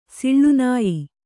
♪ siḷḷu nāyi